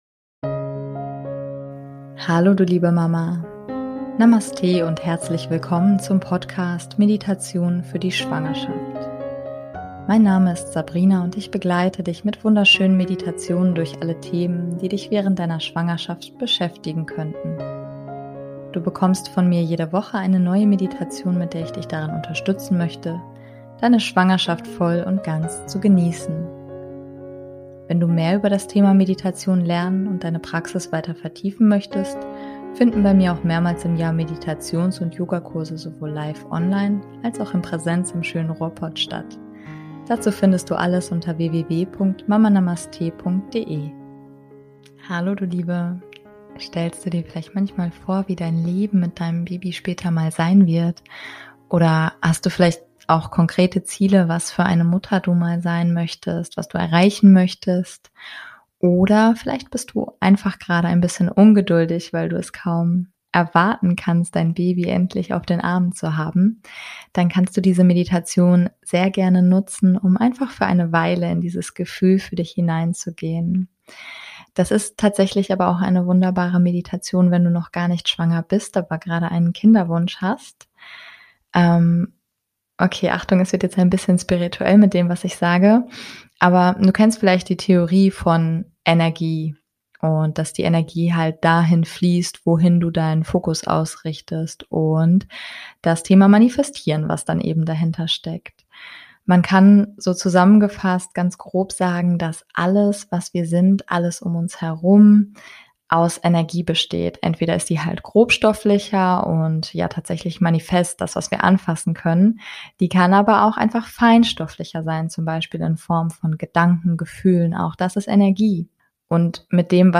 Dann kannst du diese Meditation nutzen, um einfach für eine Weile in dieses Gefühl hineinzugehen. Es ist aber auch eine wunderbare Meditation, wenn du noch nicht schwanger bist, aber gerade einen Kinderwunsch hast.